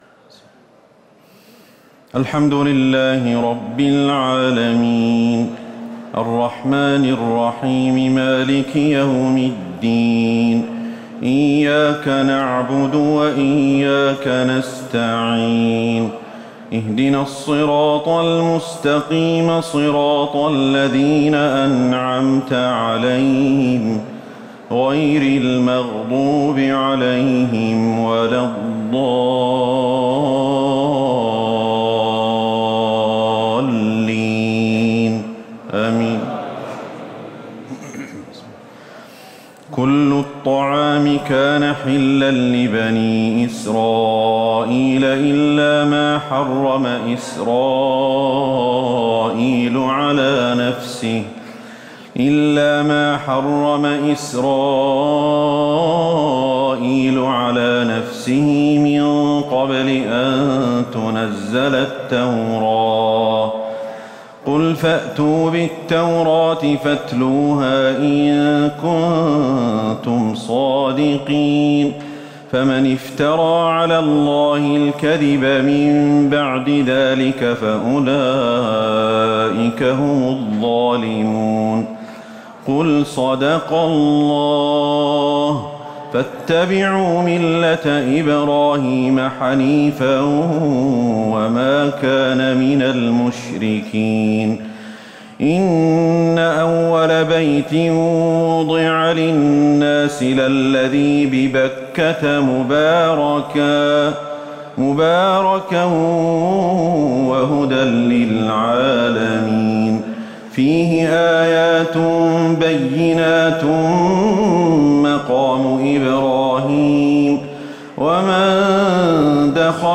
تهجد ليلة 24 رمضان 1438هـ من سورة آل عمران (93-180) Tahajjud 24 st night Ramadan 1438H from Surah Aal-i-Imraan > تراويح الحرم النبوي عام 1438 🕌 > التراويح - تلاوات الحرمين